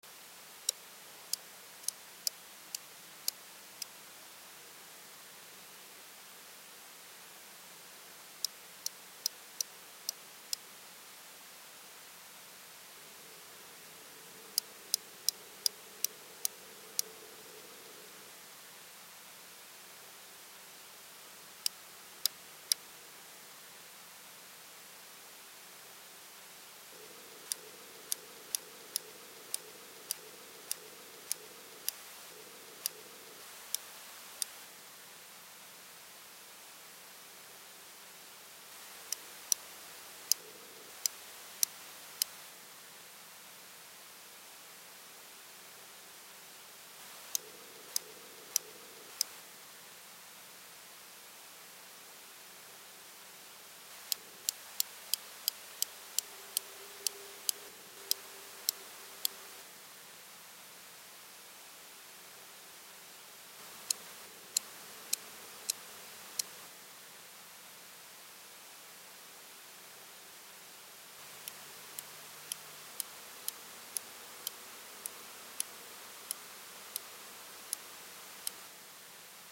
Stethophyma (=Mecostethus) grossum L. - Кобылка
болотная большая показать фото показать фото показать фото показать фото показать фото показать фото показать фото показать фото другие фото звуковой файл звуковой файл